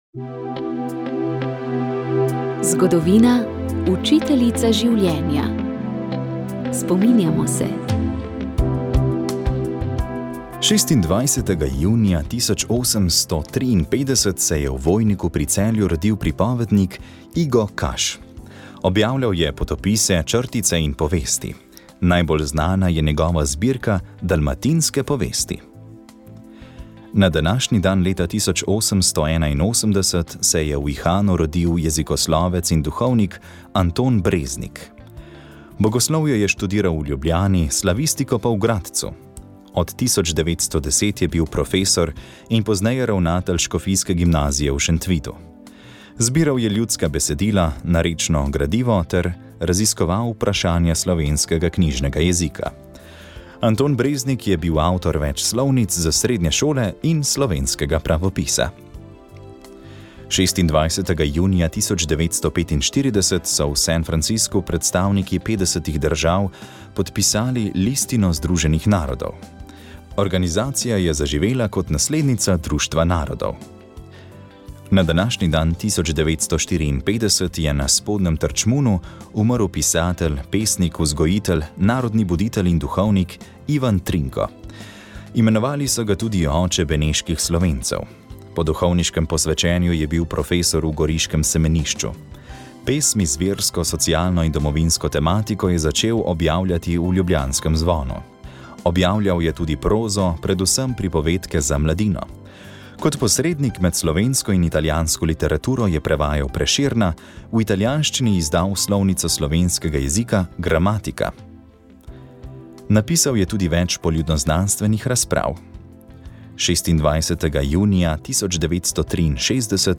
Več imen je znanih, s katerimi opisujemo ljudski instrument, ki je svoje dni spremljal ljudi ob različnih priložnostih. Najdlje se je lončeni bas ohranil v Beli krajini, kjer mu rečejo gudalo. Čez odprtino lončenega lonca je napet svinjski mehur, v sredini je paličica, in značilni zvok ustvarjamo tako, da vlažno paličico vlečemo gor in dol.